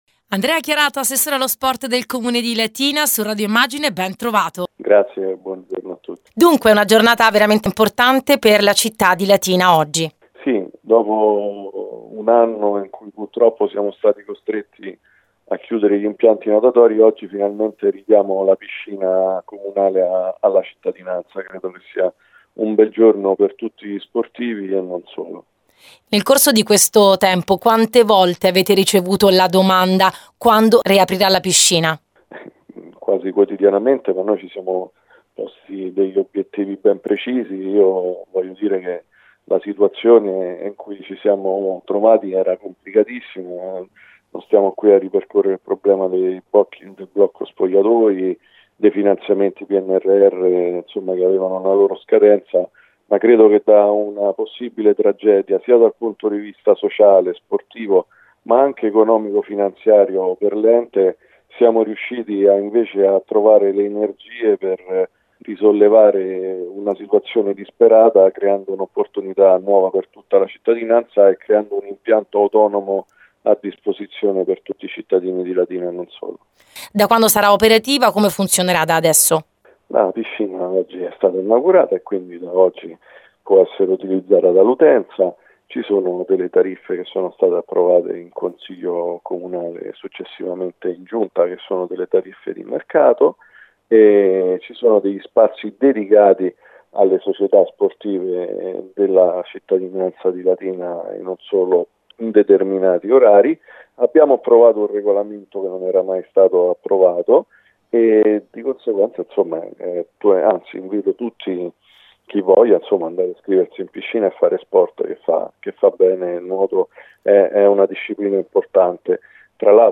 L’assessore allo Sport del Comune Andrea Chiarato
andrea_chiarato-piscina.mp3